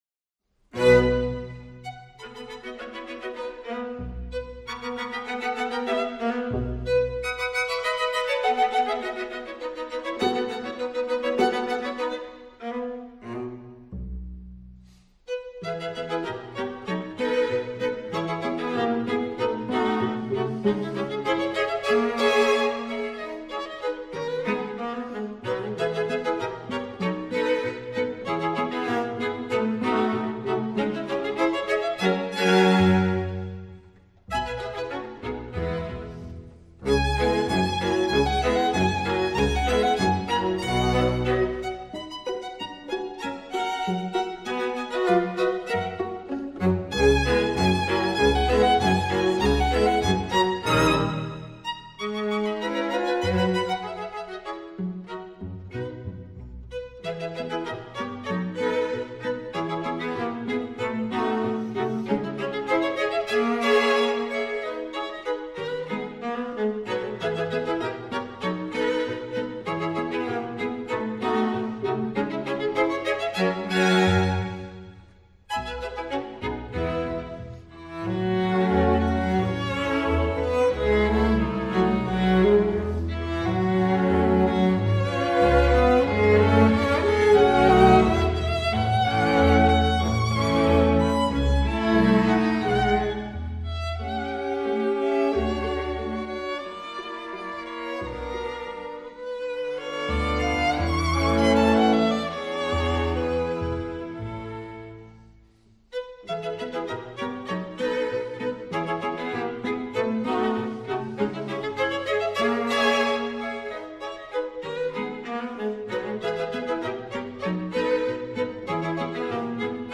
for 2 Violins, Viola, Cello and Bass or
3 Violins, Viola, Cello and Bass